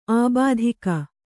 ♪ ābādhika